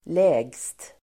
Uttal: [lä:gst]